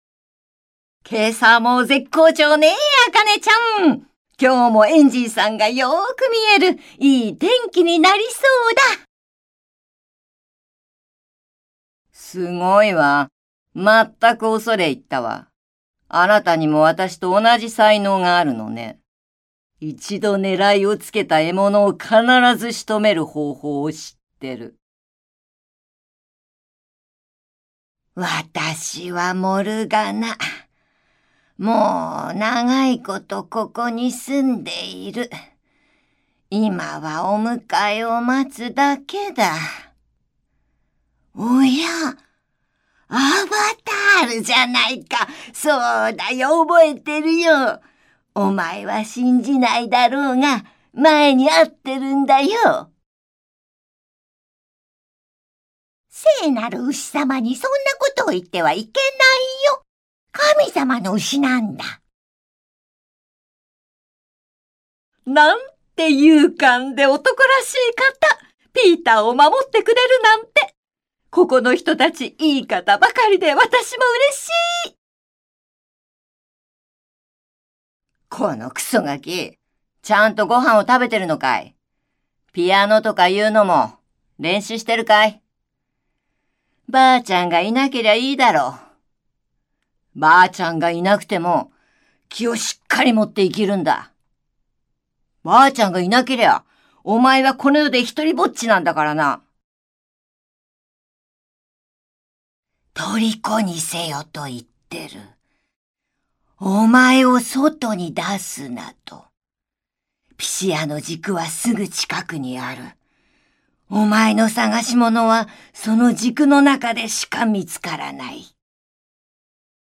ボイスサンプル
自己紹介
セリフ
ナレーション